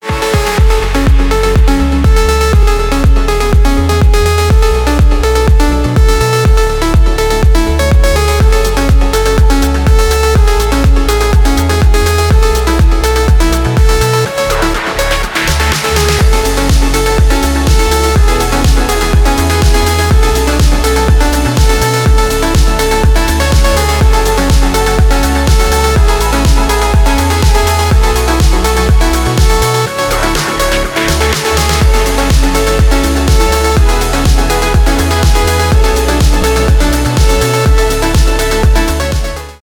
клубные , progressive house